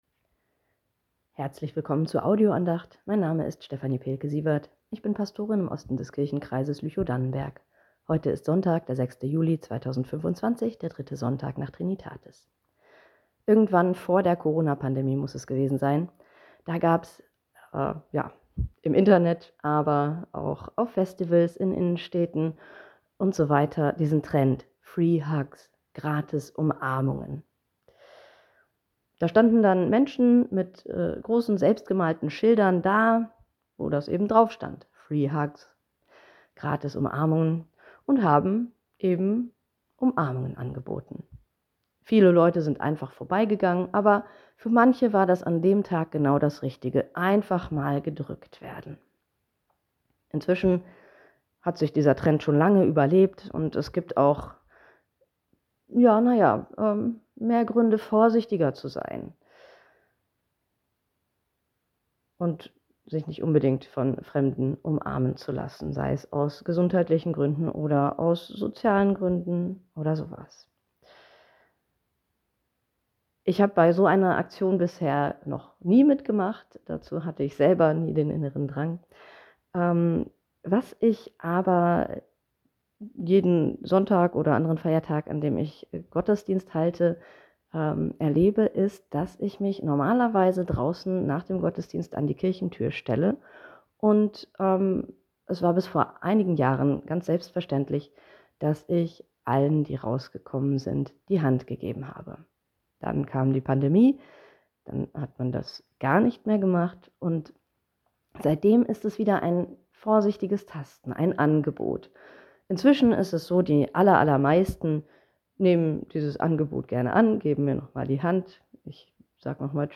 Tag der offenen Arme ~ Telefon-Andachten des ev.-luth.